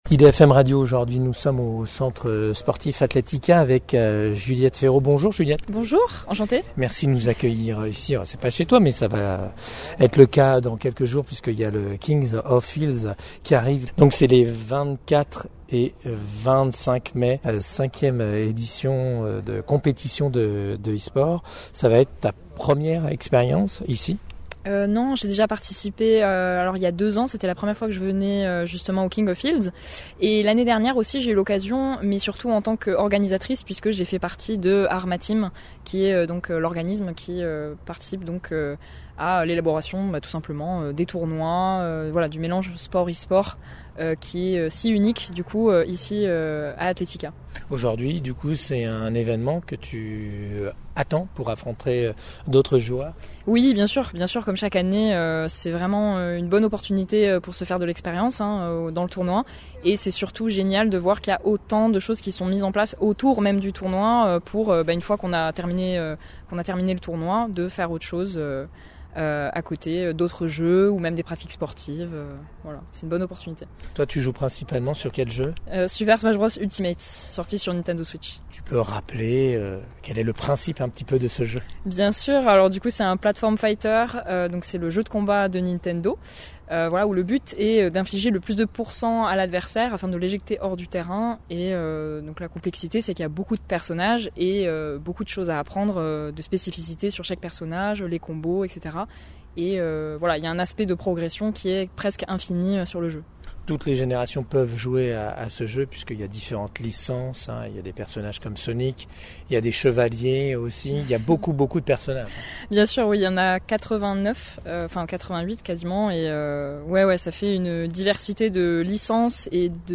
Elle a partagé avec nous son parcours inspirant, marqué par une implication très tôt dans le jeu vidéo, initiée par son entourage familial.